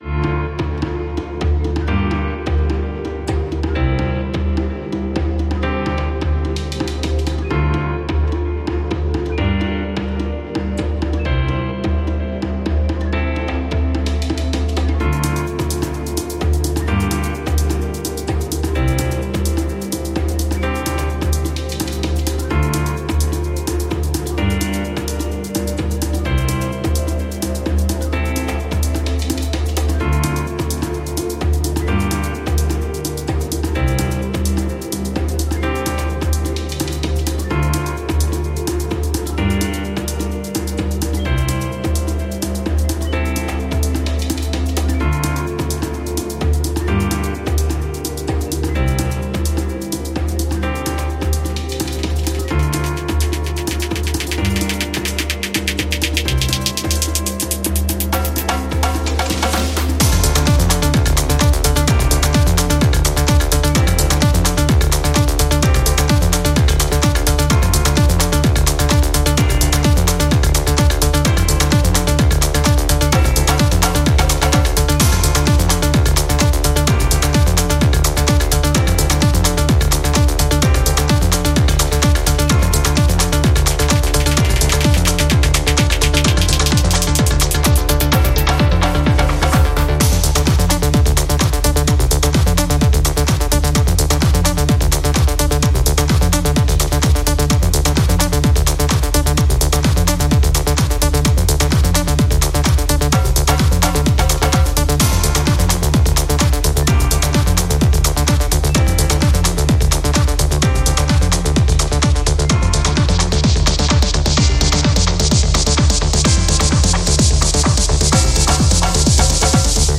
Musique dynamique libre de droit pour vos projets.